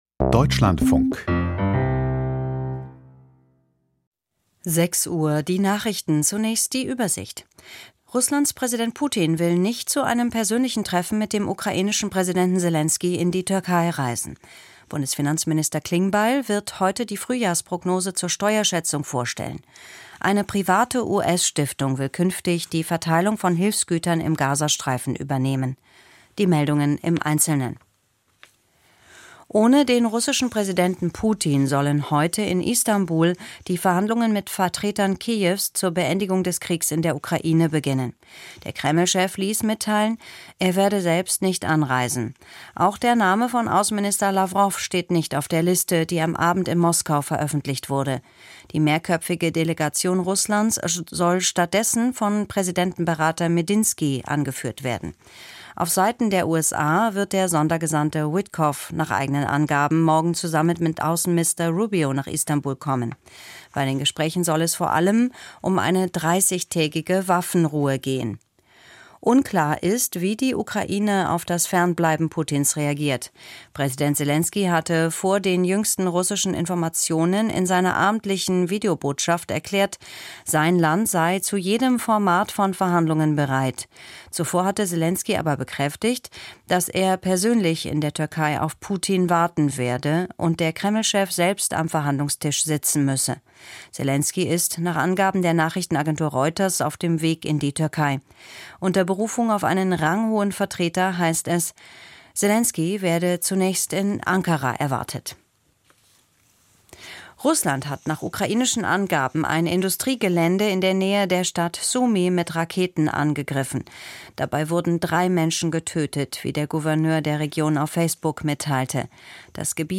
Die Nachrichten vom 15.05.2025, 06:00 Uhr